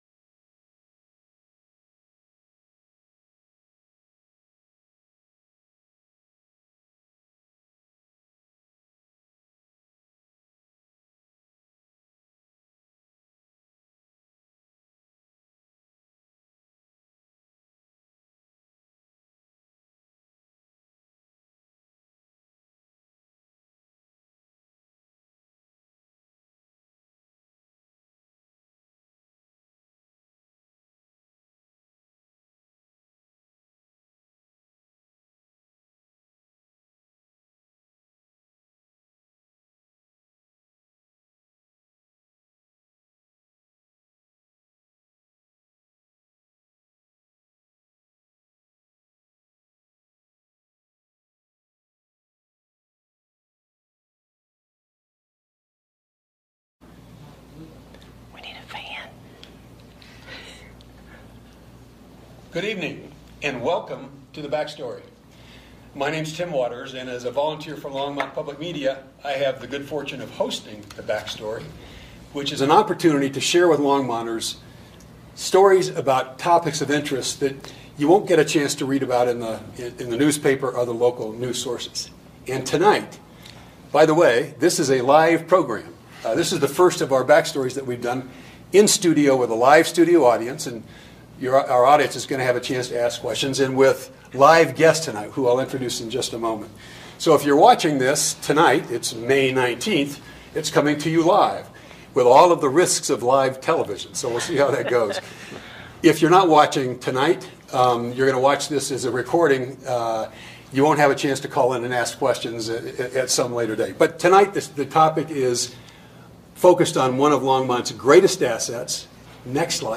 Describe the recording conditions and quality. The-Backstory-on-Nextlight-Live-at-Longmont-Public-Media-1.mp3